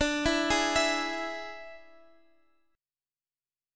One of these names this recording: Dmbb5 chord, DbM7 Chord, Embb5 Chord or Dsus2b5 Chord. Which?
Dsus2b5 Chord